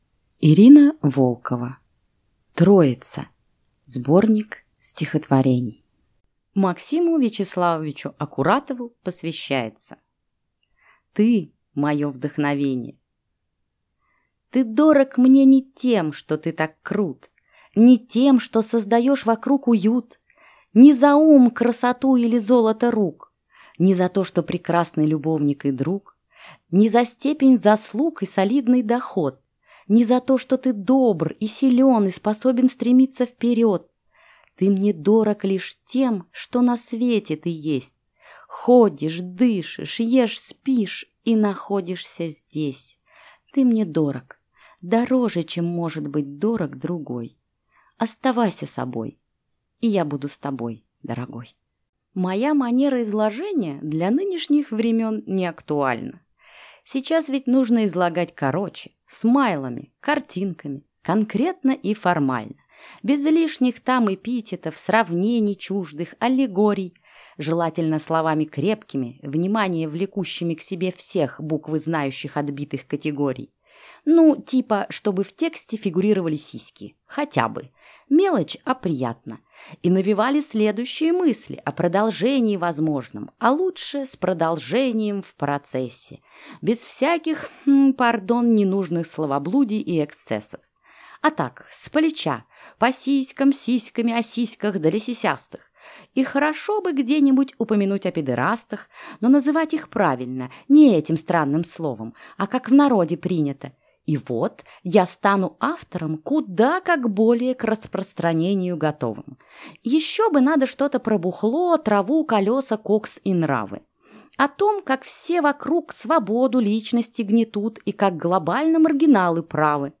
Аудиокнига Троица | Библиотека аудиокниг